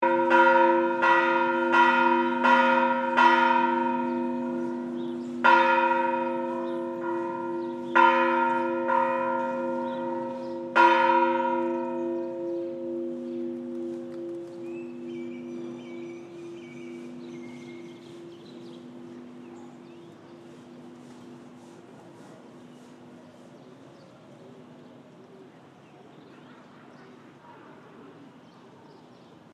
La Merced Bells at Antigua. Stereo 48Khz 24bit.
——————— This sound is part of the Sonic Heritage project, exploring the sounds of the world’s most famous sights.